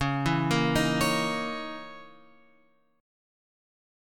C# Minor 6th Add 9th